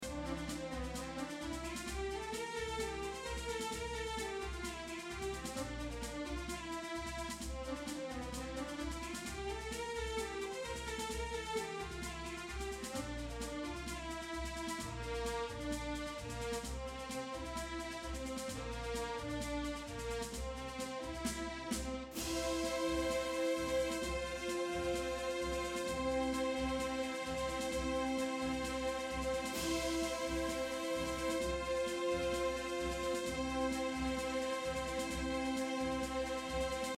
Loop Underscore